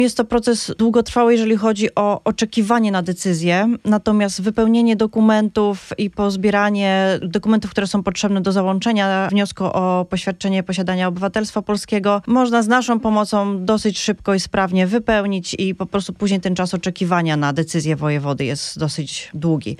O tym jak zdobyć polski dokument i dlaczego jest on ważny, wyjaśnia w studiu Radia Deon konsul Bernadetta Pałka-Maciejewska, z Konsulatu Generalnego RP w Chicago, szefowa wydziału paszportowego.